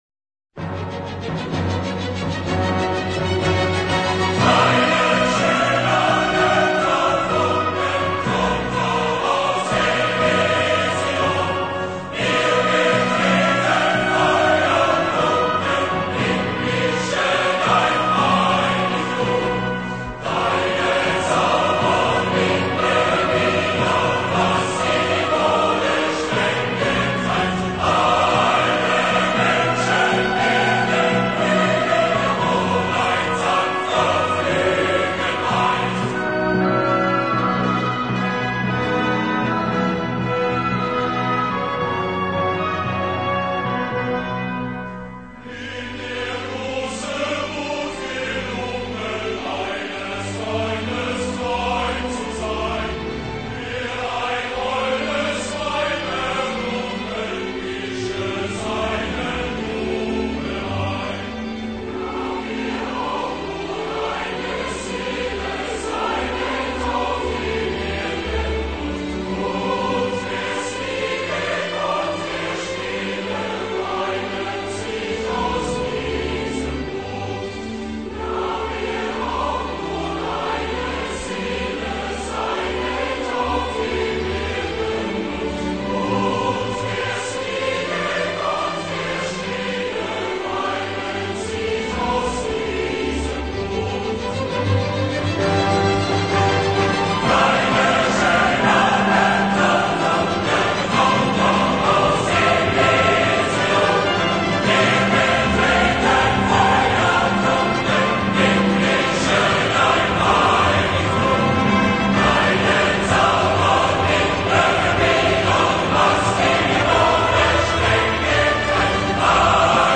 Die Hymne